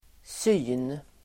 Uttal: [sy:n]